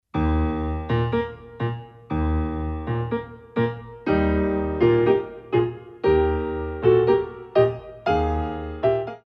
35. Habanera